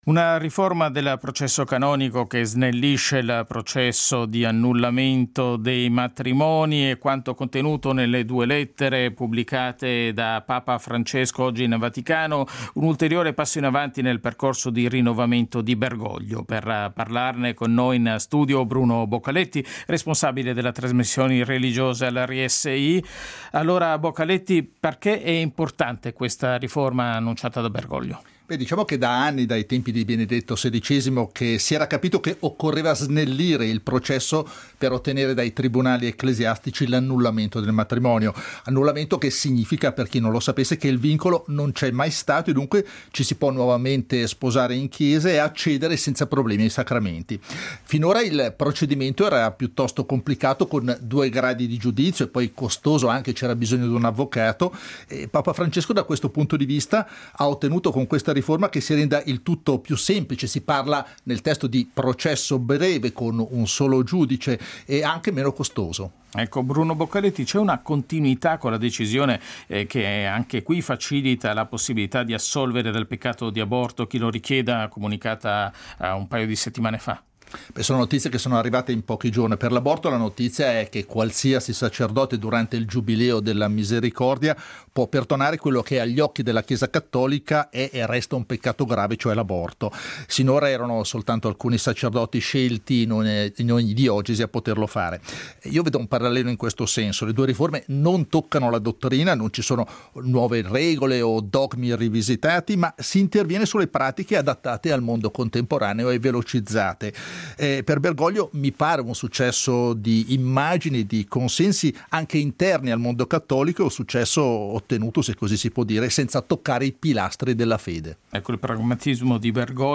le considerazioni in studio